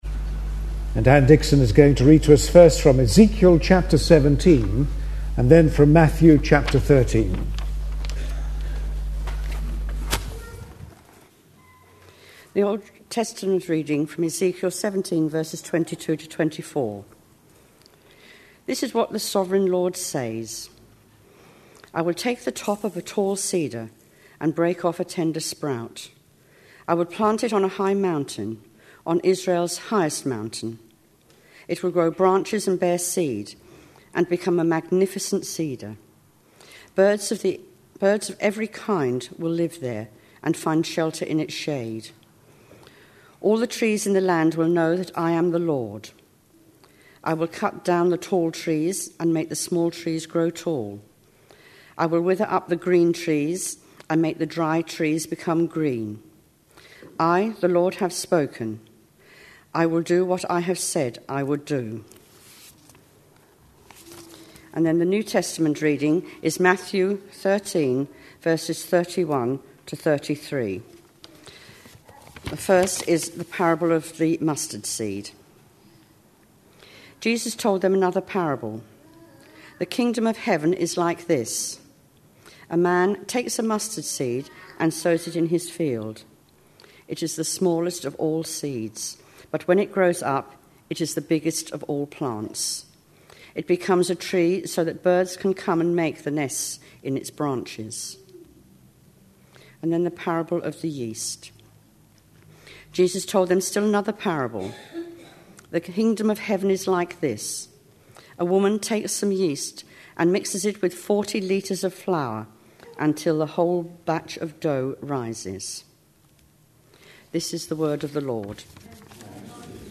A sermon preached on 26th September, 2010, as part of our Parables of Matthew series.